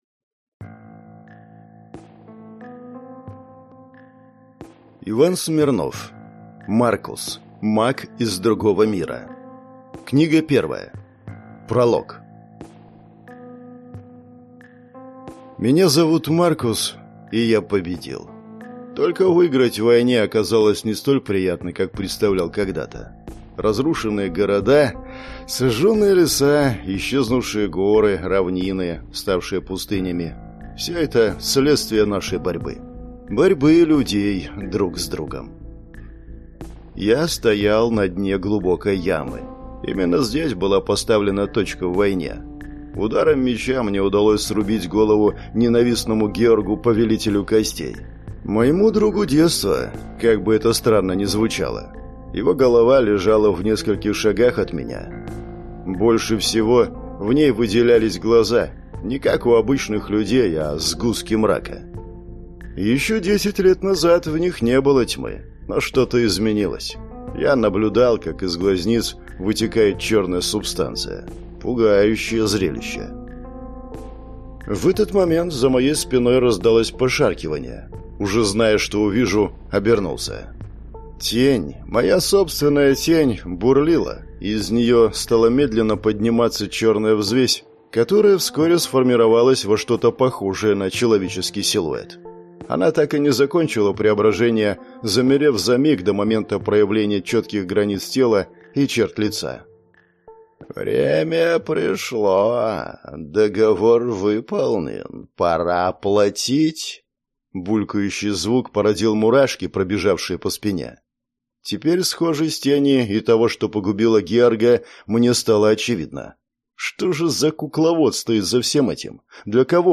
Аудиокнига Маркус. Маг из другого мира. Книга 1 | Библиотека аудиокниг